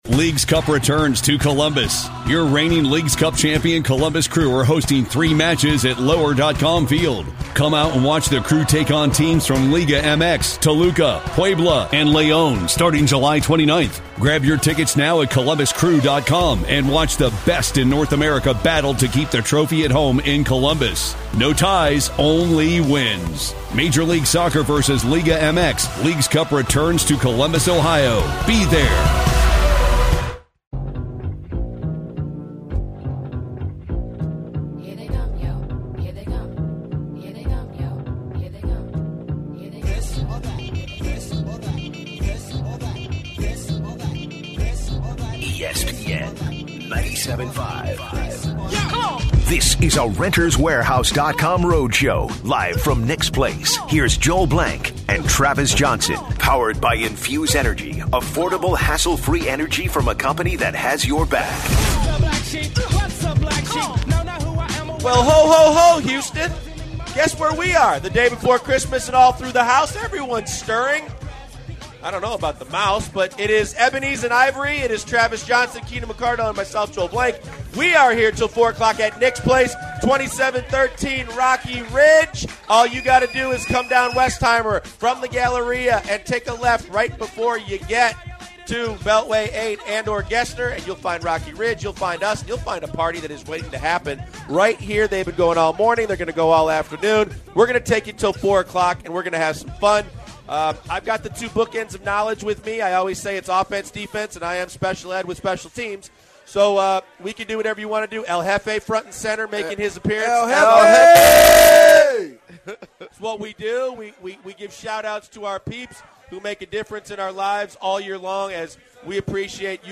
on-site at Nick's Place